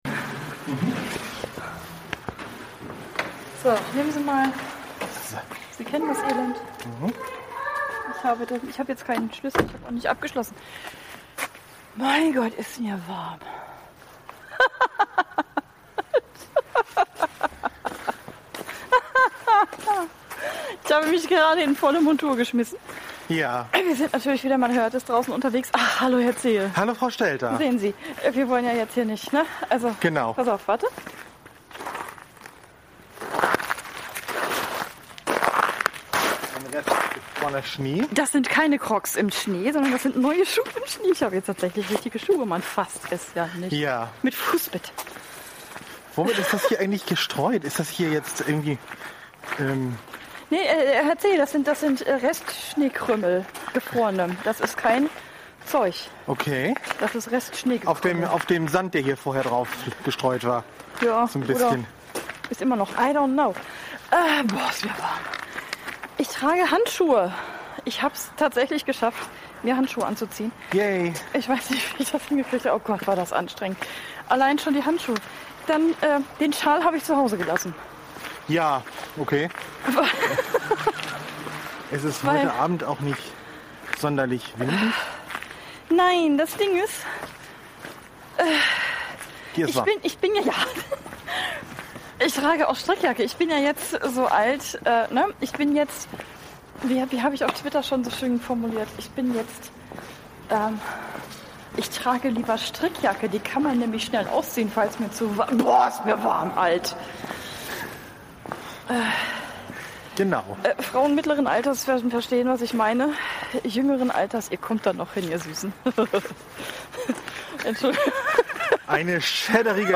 Podcast Schneespaziergang Erweiterte Suche Schneespaziergang vor 10 Jahren Sabbelei im Schnee 25 Minuten 23.32 MB Podcast Podcaster Wir sabbeln.